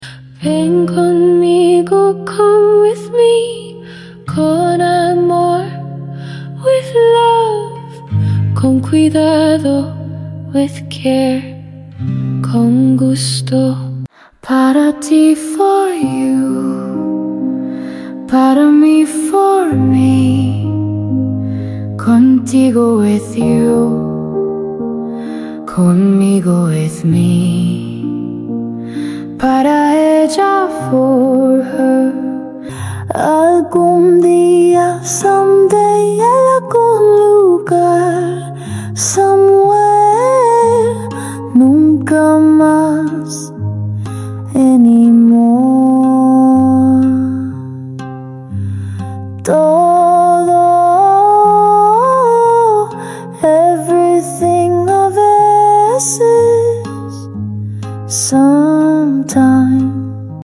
Cada frase está acompañada de ritmo y ejemplos que se te quedarán grabados fácilmente. Perfecto para practicar vocabulario, pronunciación y expresiones útiles del día a día.